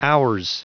Prononciation du mot ours en anglais (fichier audio)
Prononciation du mot : ours